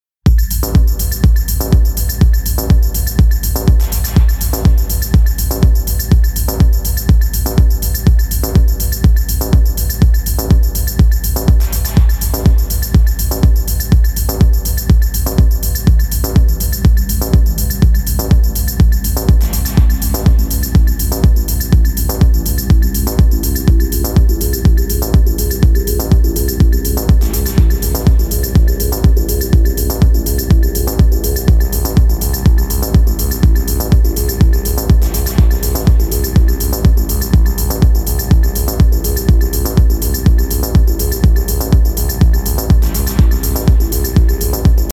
Как накрутить такой техно-бас
Это сильная реверберация низкочастотной составляющей от кика, с сайдчейнингом (затухает на удар кика). Уже сделал что-то подобное Вложения techno.mp3 techno.mp3 1,7 MB · Просмотры: 362